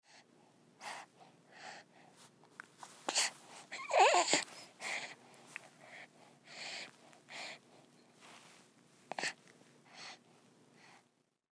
Звуки младенцев
Звук ребенок сопит во сне дите